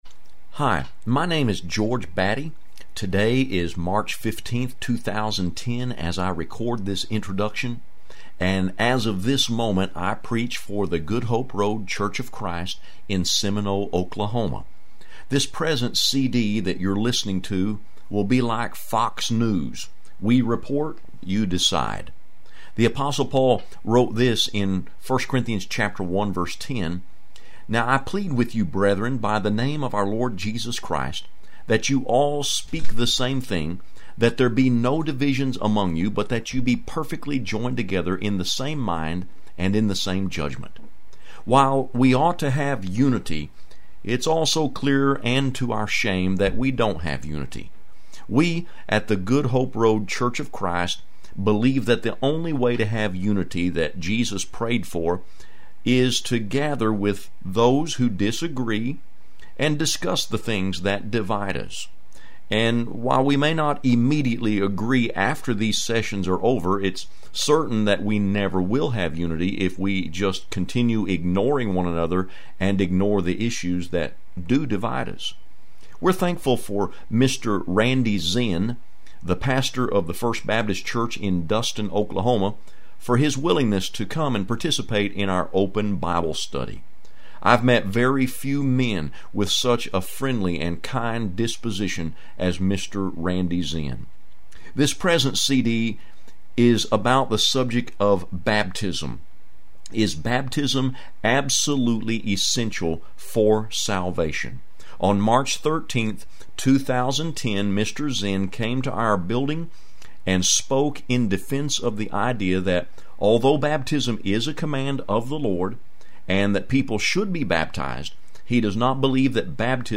“Open Bible Study” #1 – A Discussion with a Baptist Preacher: Is baptism absolutely essential for salvation?
Audio Sermons Preached on March 13, 2010